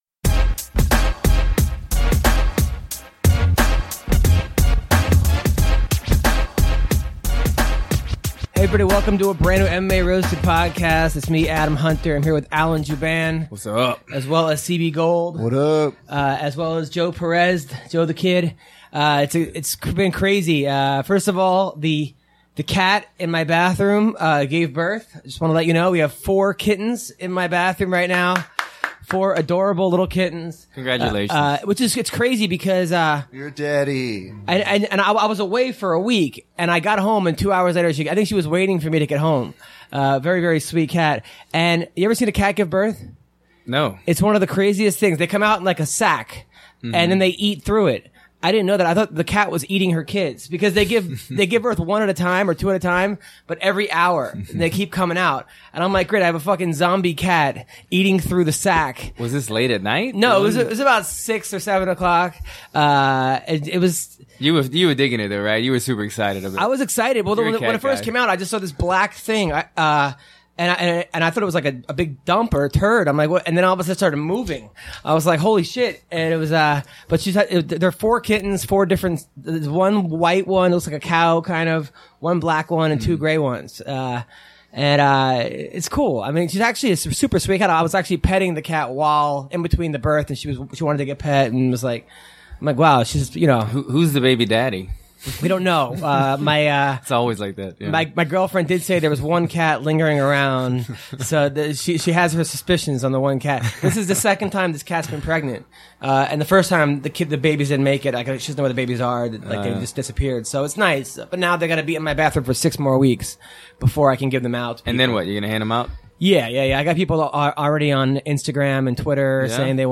UFC fighter Alan Jouban drops by the studio to talk about his career and the upcoming fights.